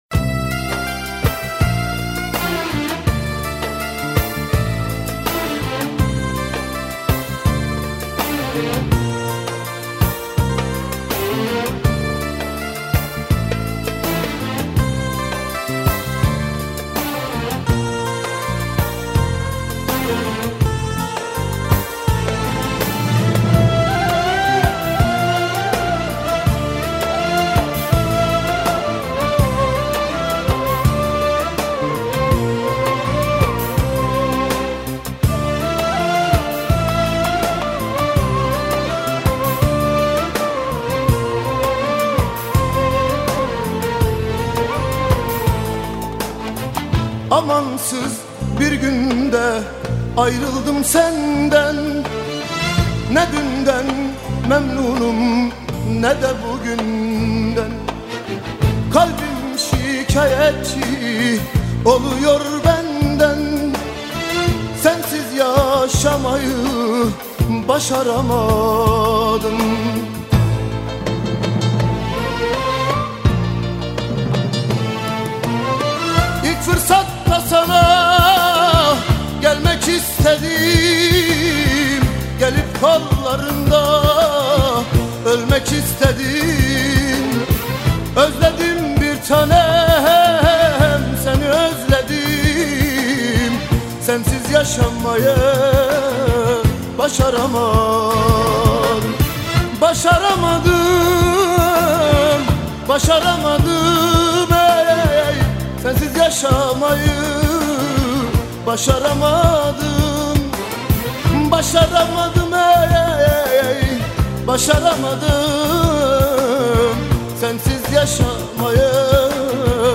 آهنگ ترکیه ای آهنگ غمگین ترکیه ای آهنگ نوستالژی ترکیه ای
این ترانه زیبا در سال ۱۹۹۸ توسط این خواننده محبوب خوانده شده